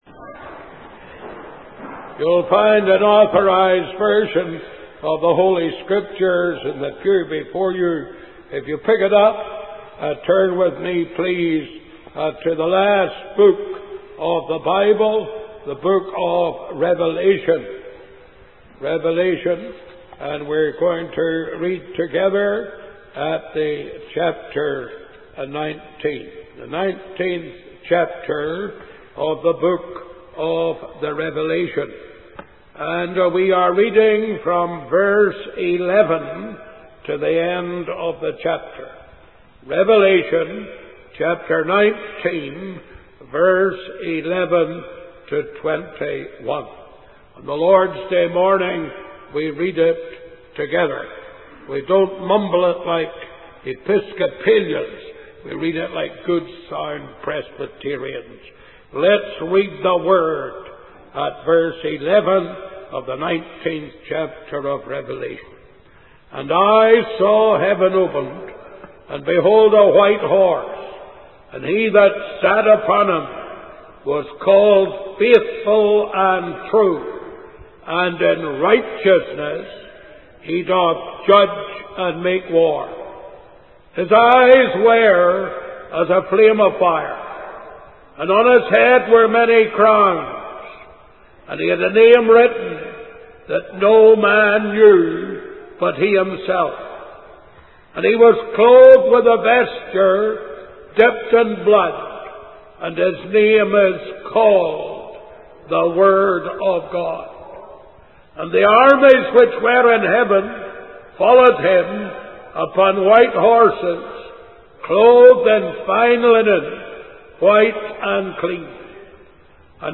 In this sermon, the preacher focuses on the final battle between Christ and the enemies of the Gospel.